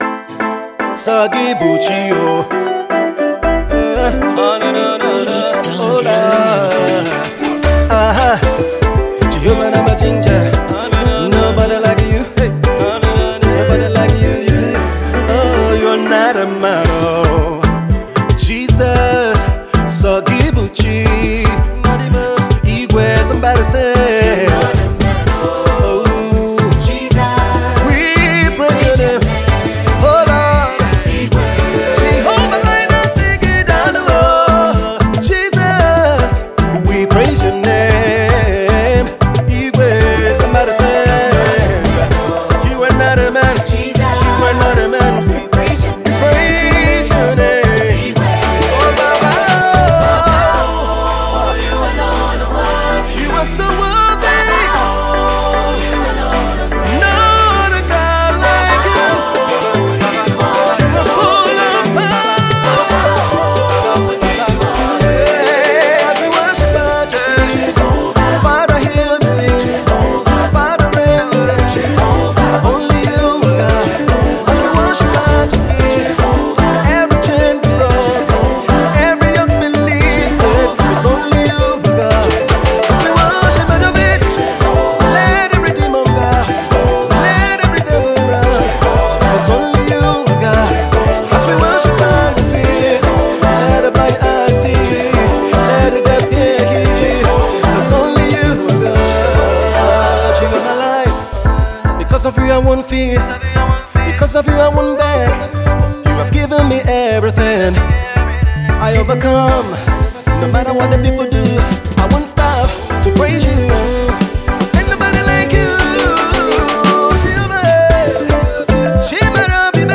is an energetic track that will get you to dance
is a mid tempo praise song
Nigerian gospel song